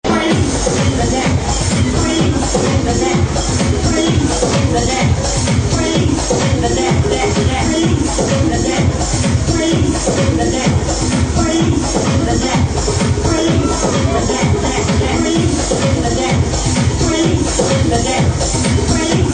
Please ID this wonderful tribal/progressive track 4 me.